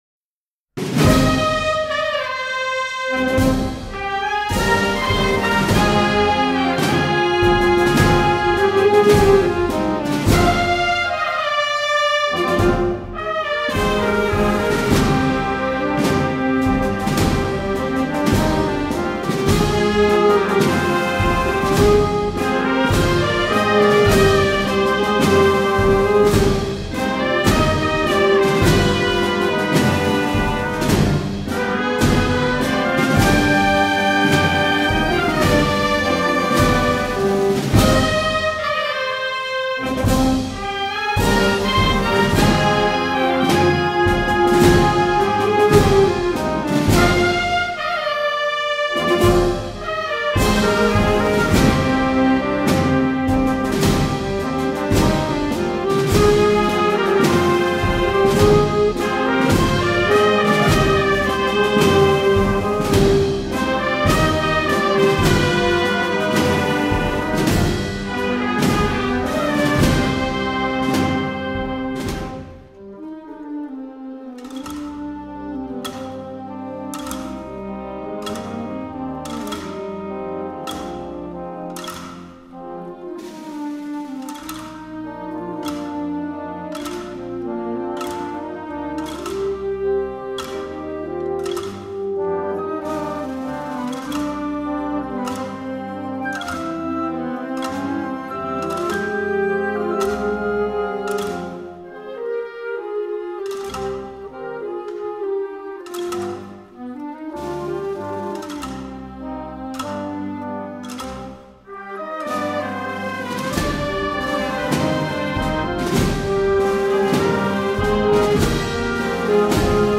Marcha Procesional para Banda de Música,